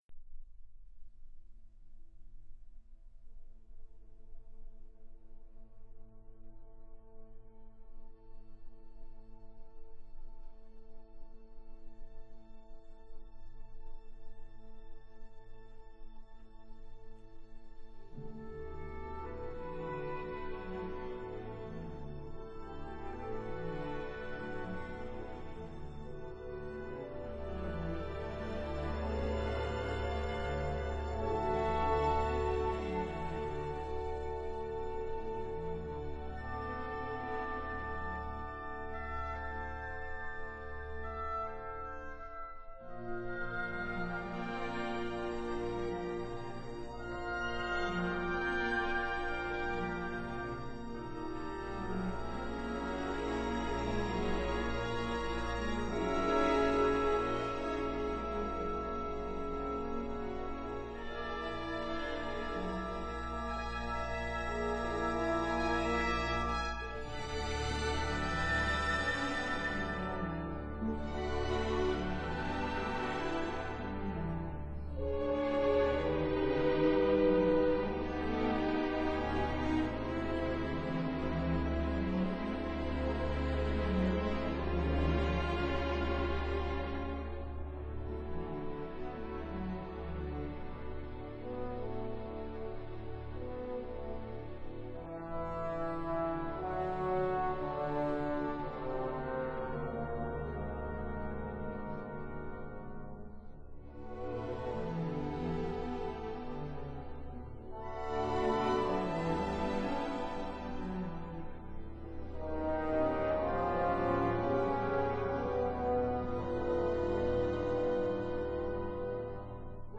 for saxophone and orchestra